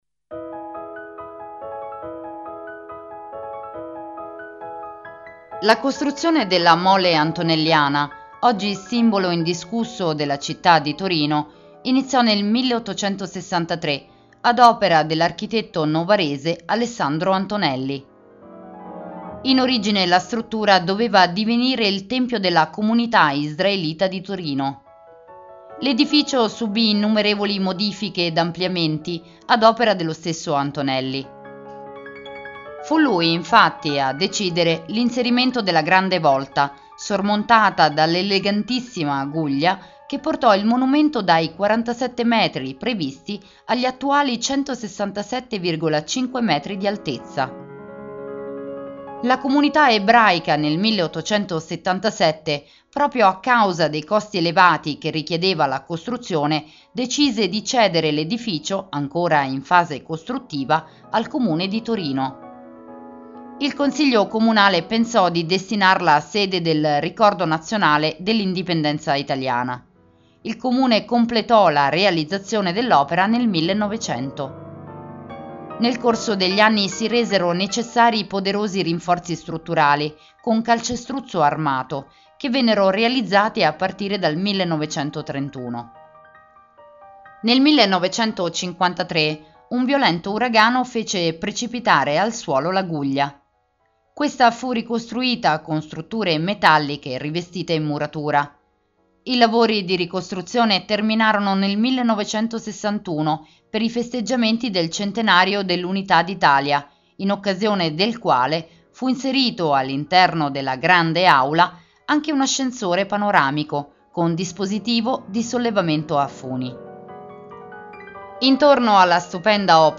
Audioguida Torino – La Mole